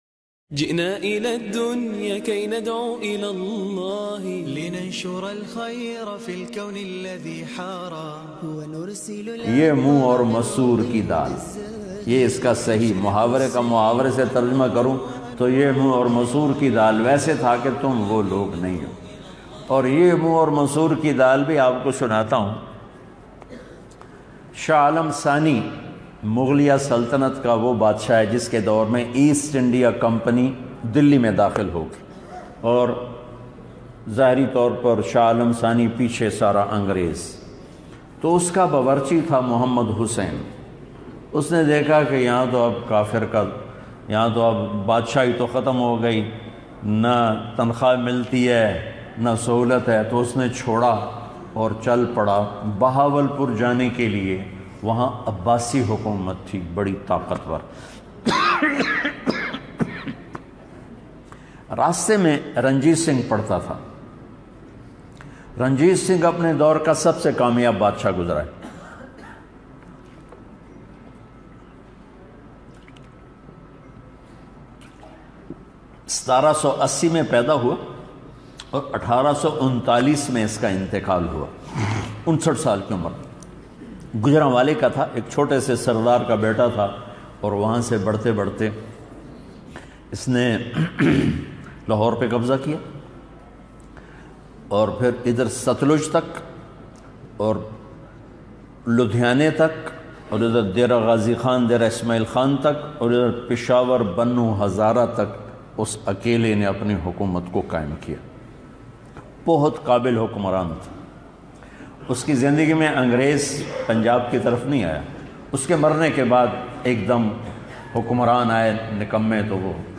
Strange stories of two kings and their merasis bayan mp3